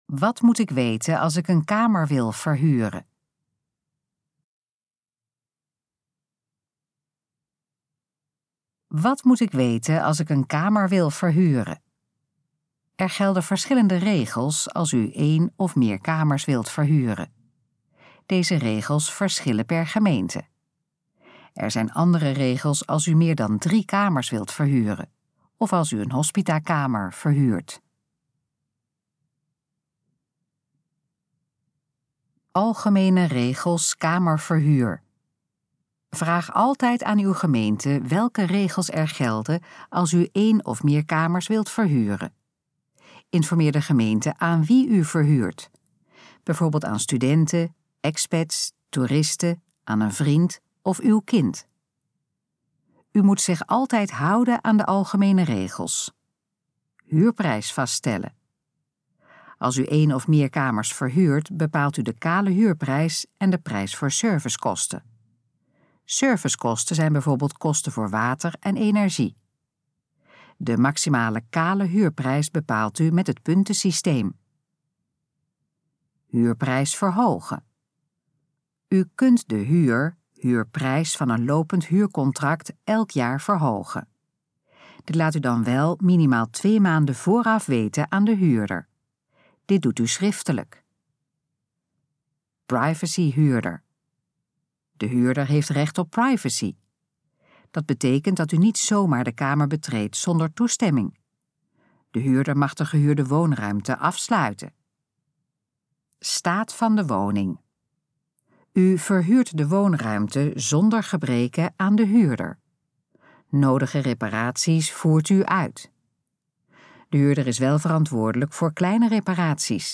Dit geluidsfragment is de gesproken versie van de pagina: Wat moet ik weten als ik een kamer wil verhuren?